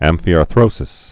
(ămfē-är-thrōsĭs)